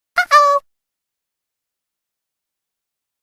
Uh Sound Effects MP3 Download Free - Quick Sounds